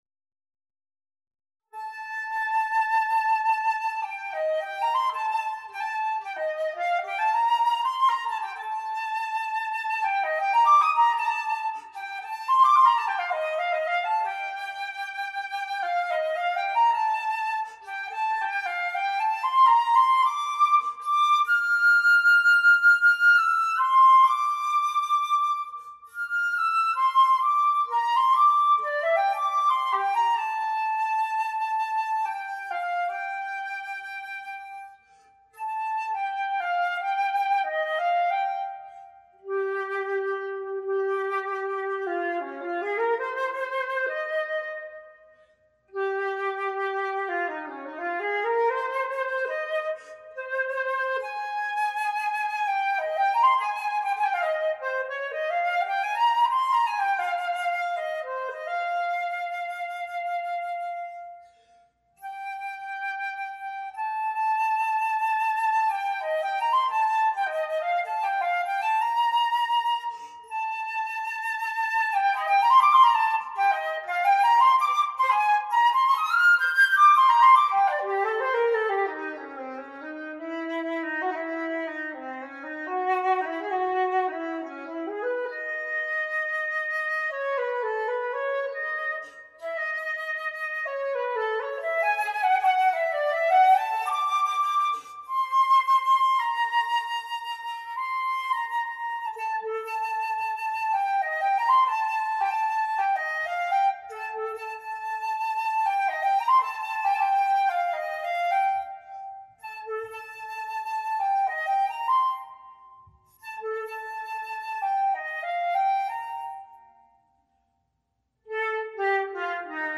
この作品は当時のヨーロッパの流行である中国趣味の影響が濃いエキゾチックな曲です。
使用楽器 /Powell Handmade Aurumite RH